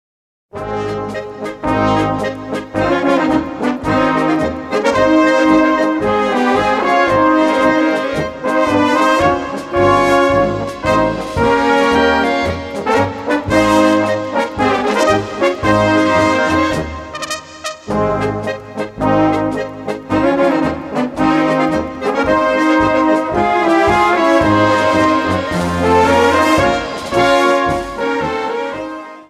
Gattung: Polka
2:39 Minuten Besetzung: Blasorchester Zu hören auf